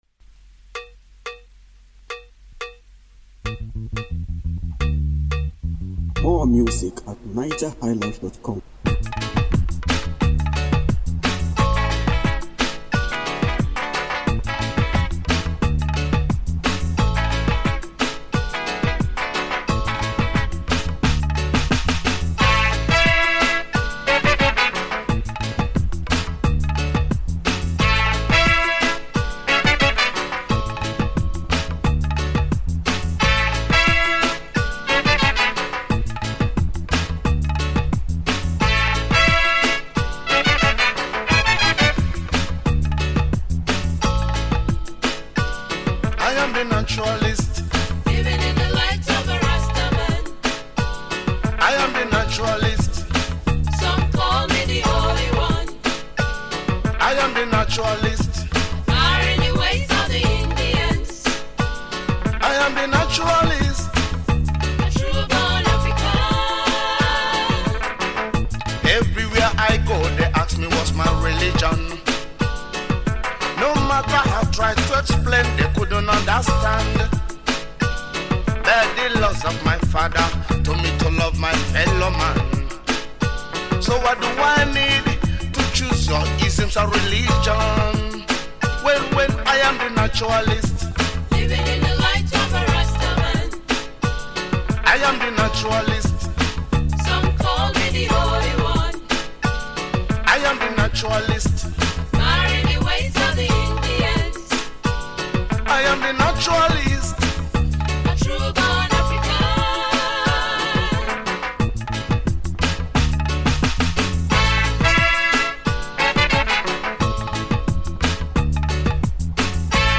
Home » Ragae
Wonderful Reggae Music